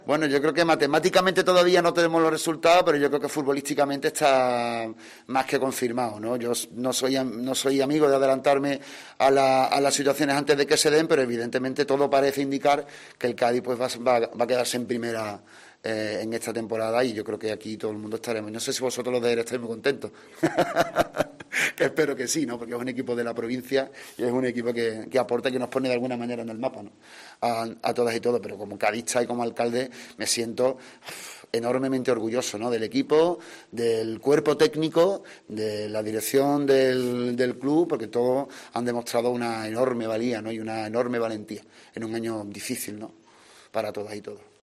El alcalde gaditano tiró de humor y un poco de guasa gaditana cuando fue preguntado por el Cádiz CF. El edil fue cuestionado por la permanencia cadista tras sumar los 40 puntos que virtualmente dejan al club en Primera División.